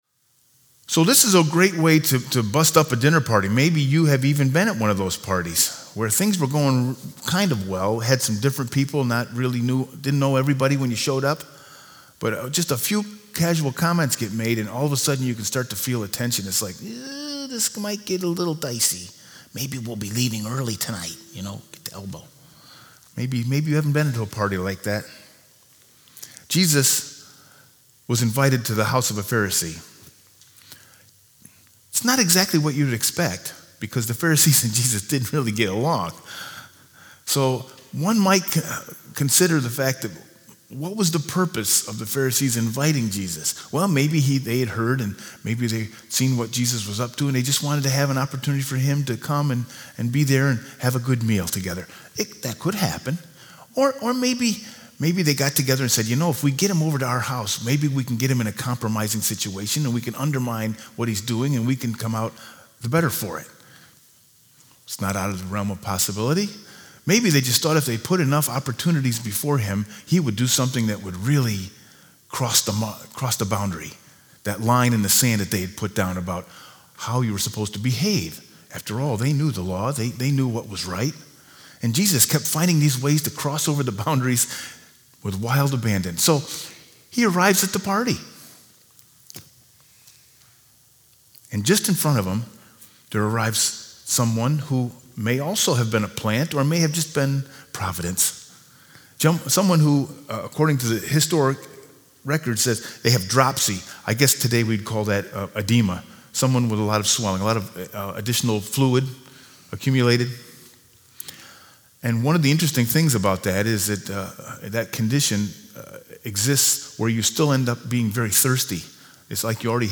Sermon 9/1/2019